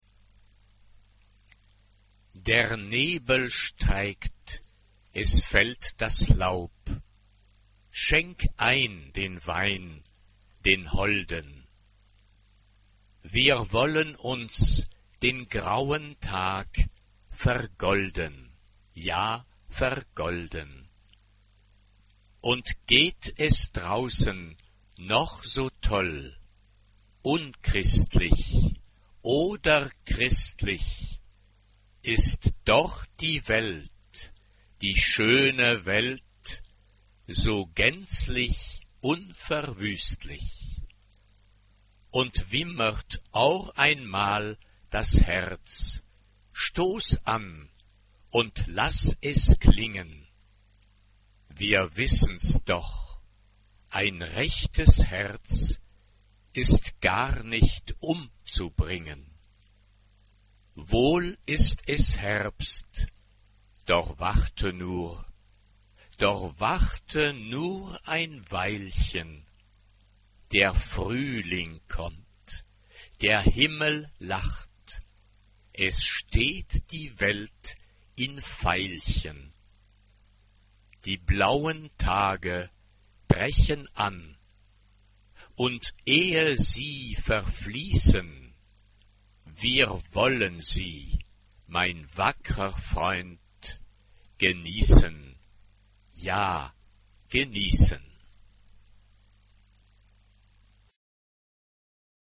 SATB (4 voix mixtes) ; Partition complète.
Chanson à boire.
Tonalité : si bémol majeur